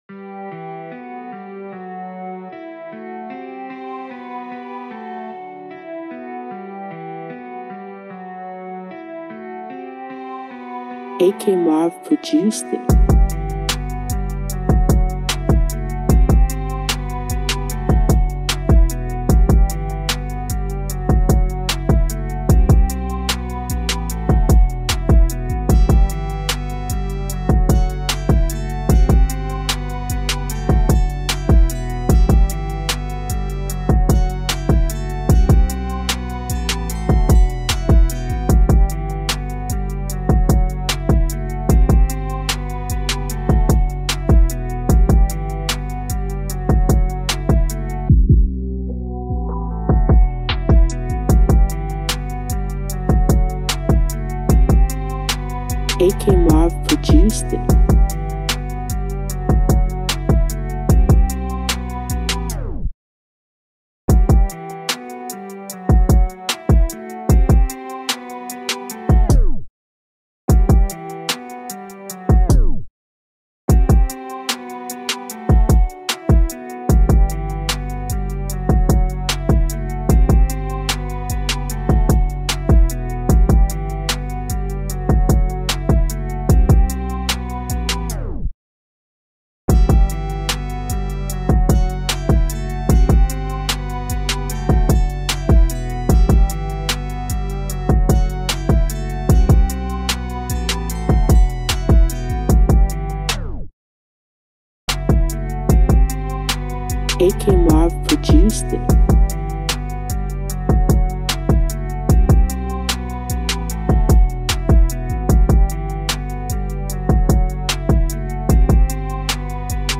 This Hip Hop Beat remake is a perfect match for the song.